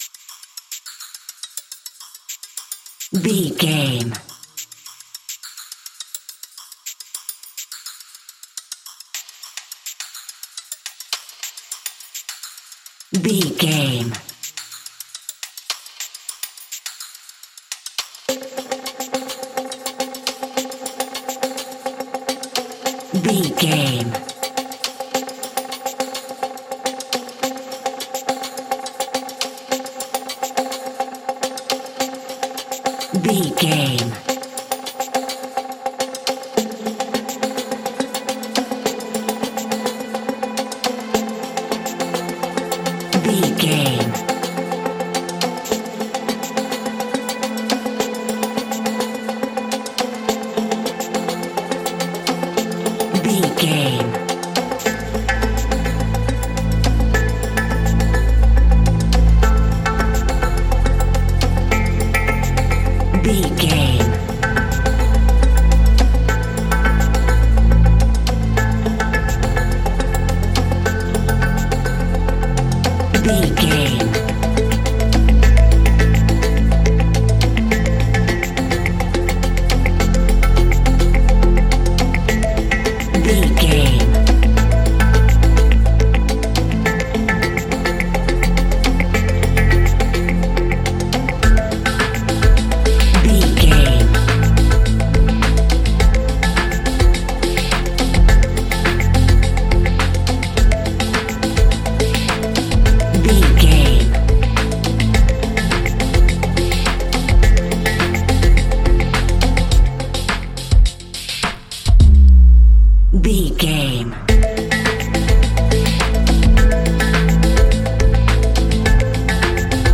Ionian/Major
electronic
techno
trance
synths
synthwave